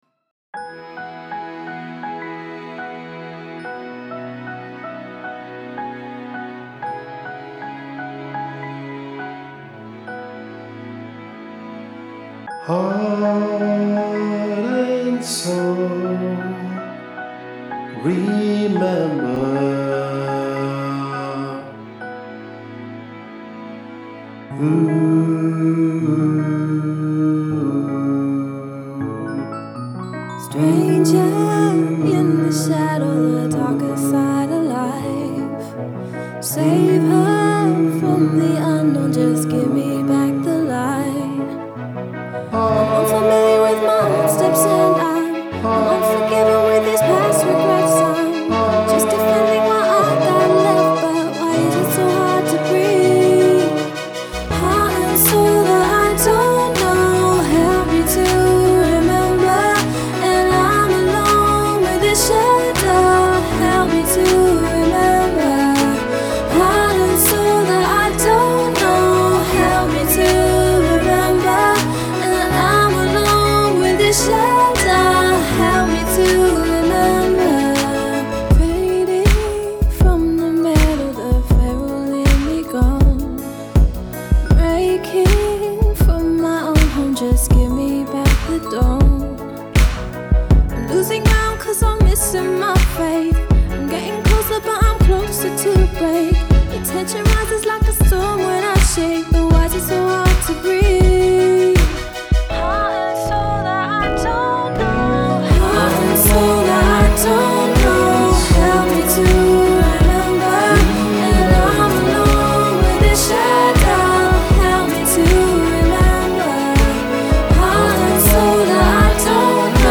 Choir
shadow-middle.mp3